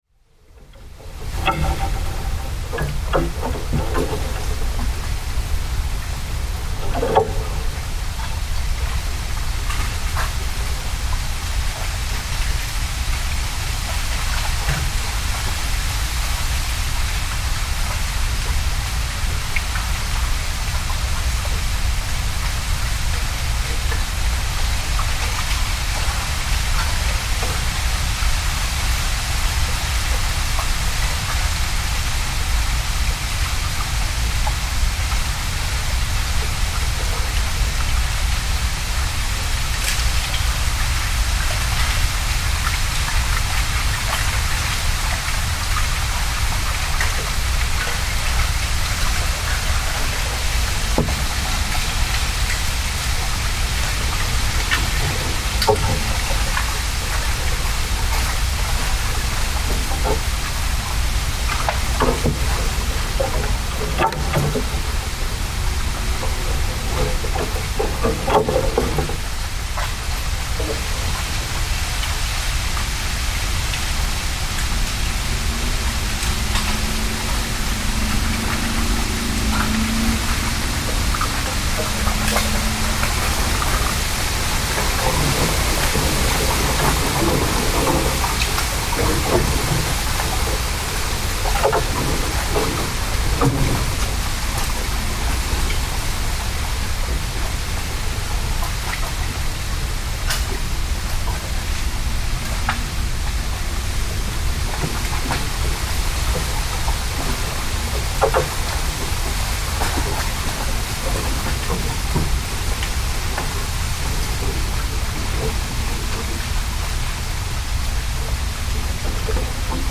Kyoto bamboo nocturne
At night, in a bamboo grove in Kyoto, the wind threads its way through the tall stalks, bending them until they sway and knock together with hollow tones.
The sound is both delicate and immense — a shifting chorus of rattles, sighs, and murmurs that rise and fall like waves. Each gust carries a new texture: sometimes a low, resonant moan as the bamboo bows deeply, sometimes a fine, trembling hiss as leaves brush against one another in countless, shimmering layers. In the stillness between, silence feels almost physical — a pause that makes each return of the wind seem like a hidden spirit passing through. The grove itself becomes an instrument, played by the night air.